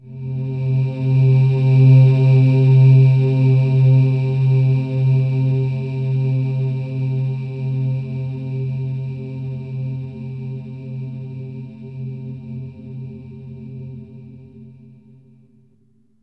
AMBIENT ATMOSPHERES-5 0009.wav